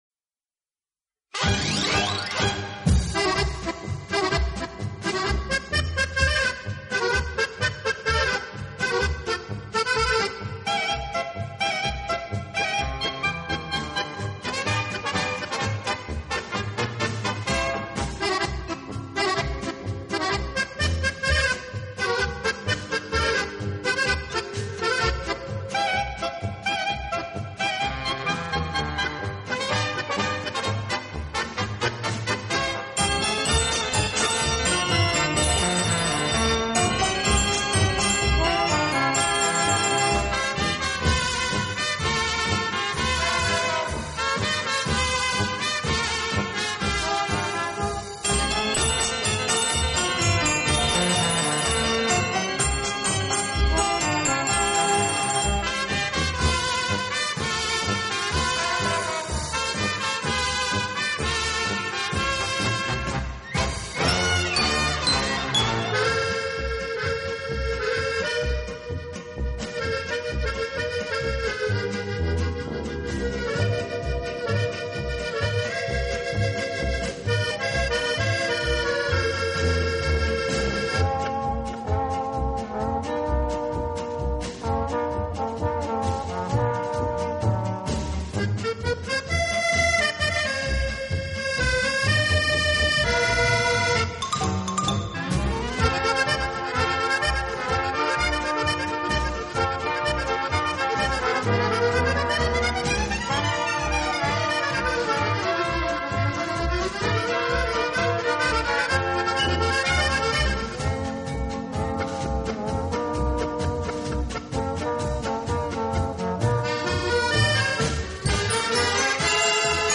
手风琴